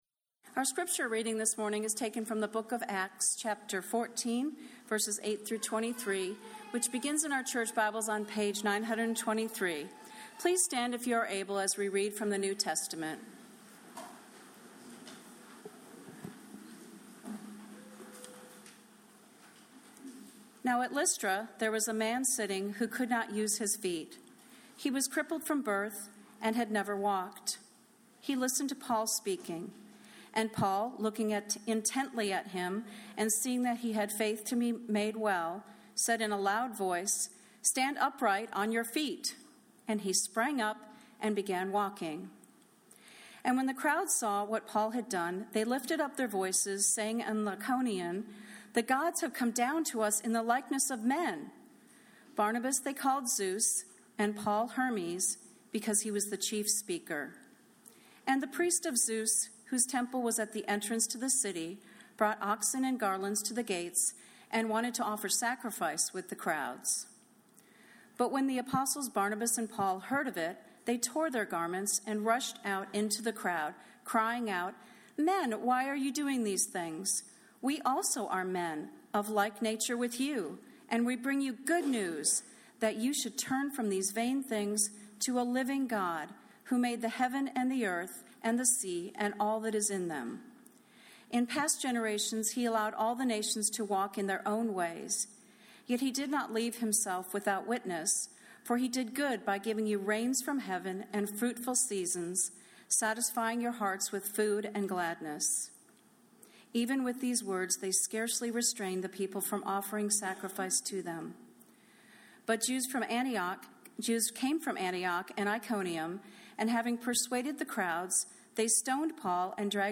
sermon-audio-7.19.15.mp3